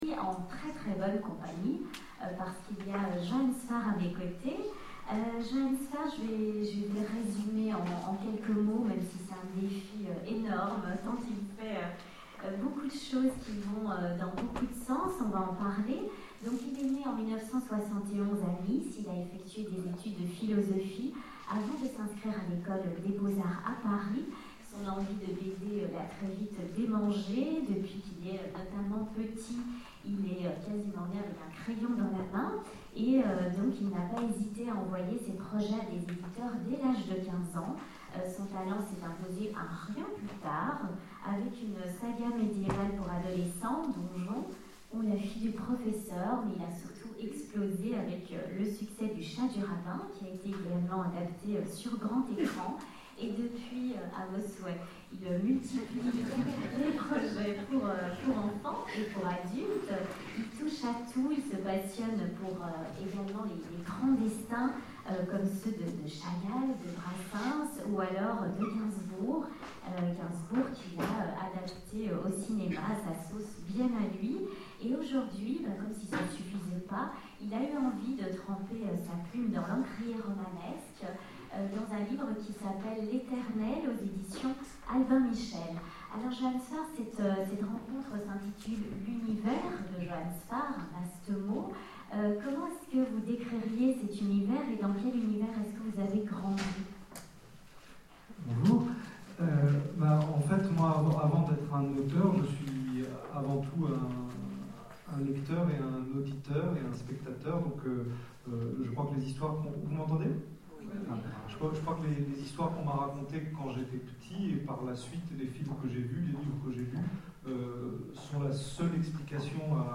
- le 31/10/2017 Partager Commenter Etonnants Voyageurs 2013 : Conférence Les univers de Joann Sfar Télécharger le MP3 à lire aussi Joann Sfar Genres / Mots-clés Rencontre avec un auteur Conférence Partager cet article